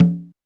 Drums_K4(29).wav